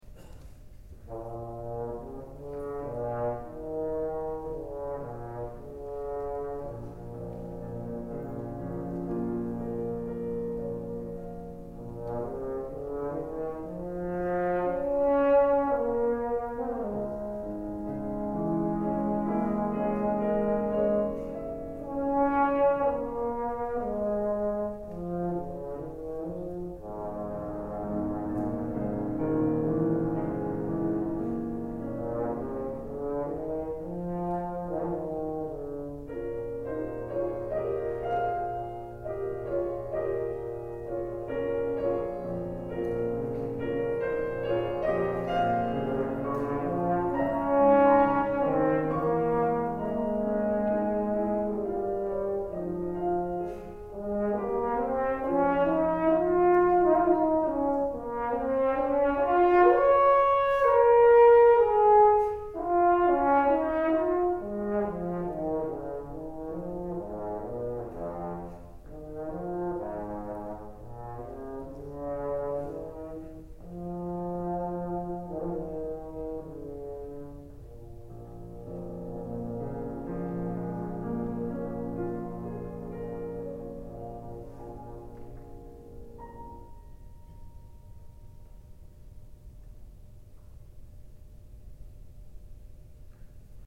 This summer I arranged some music for horn and piano, including  Six Studies in English Folk-Song by Ralph Vaughan Williams, and the “Meditation” from Thaïs, by Jules MassenetThere are some beautiful melodies in these pieces, and I thought they might transfer well to the horn.
This version is purposefully written in an under-utilized range of the horn, and working on it really helped improve my sound and musicality in the lower register.
And here’s a recording of me playing the first movement on a recent recital
piano